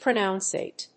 pronounciate.mp3